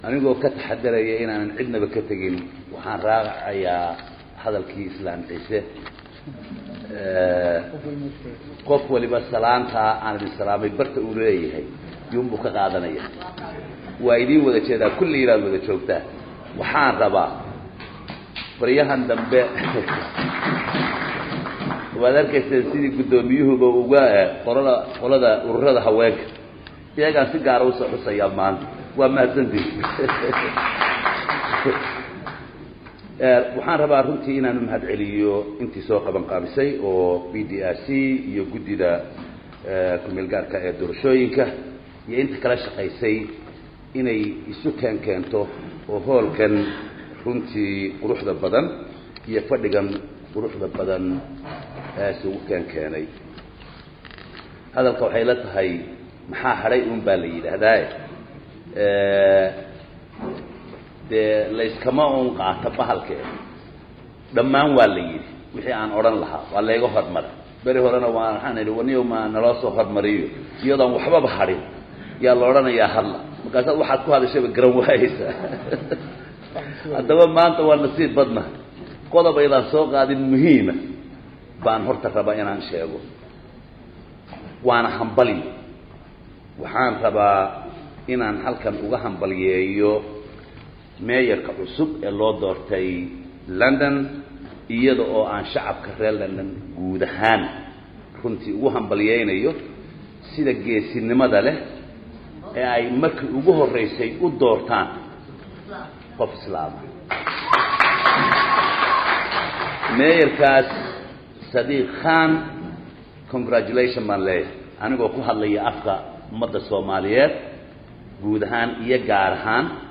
11 May 2016 (Puntlandes) Waxaa maanta lagu qabtay Xarunta Hay’adda PDRC Garowe Munaasabadd lagu daah furayey Guddiga KMG ah e doorashooyinka Punland
Dhagayso Madaxweyne ku-xigeenka dowladda Puntland